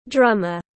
Nghệ sĩ trống tiếng anh gọi là drummer, phiên âm tiếng anh đọc là /ˈdrʌmər/.
Drummer /ˈdrʌmər/